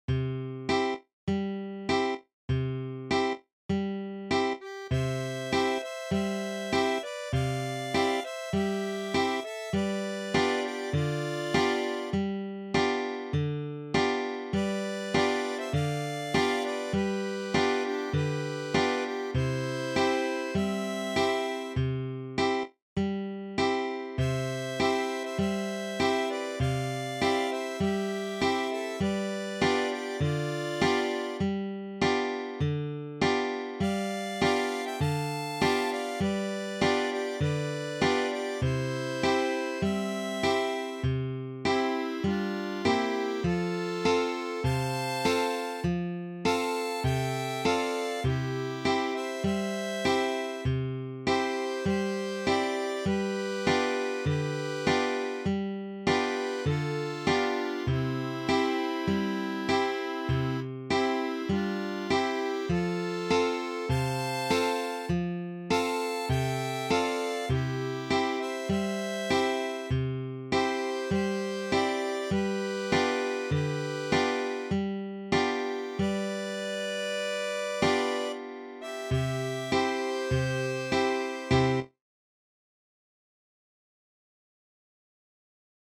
(Canto popolare)
Affaccia, bedda, e-ddammi ddue viole - Strumenti in Sib e chitarra.mp3